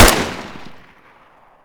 ump45_shoot.ogg